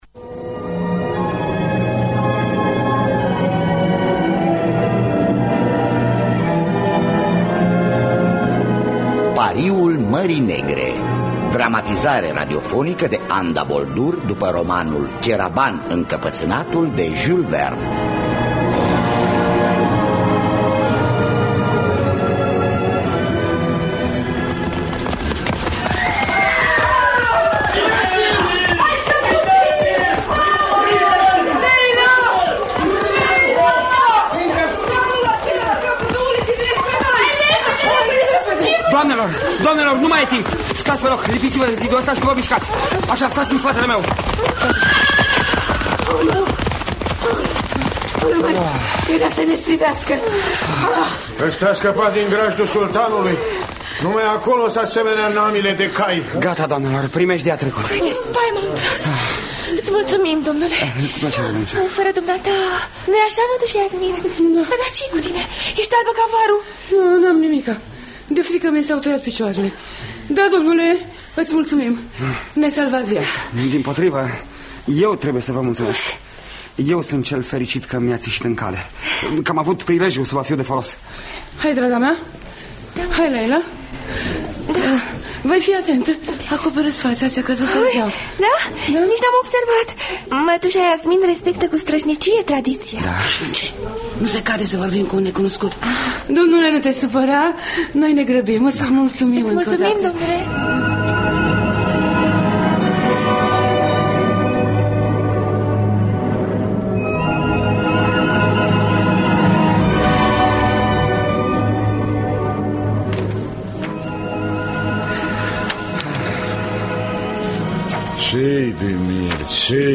Dramatizarea radiofonică de Anda Boldur.
Înregistrare din anul 1985.